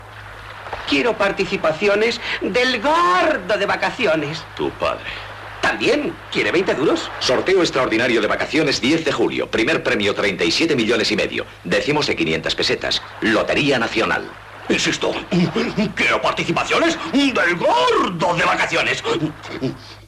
Publicitat de "El gordo de vacaciones" de Lotería Nacional Gènere radiofònic Publicitat